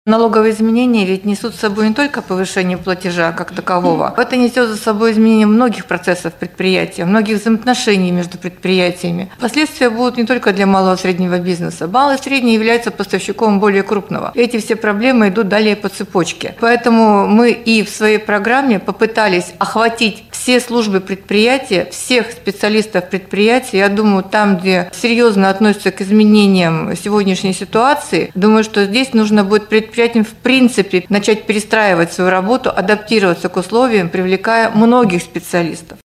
на пресс-конференции ТАСС-Урал, посвященной Межрегиональному налоговому форуму в Екатеринбурге.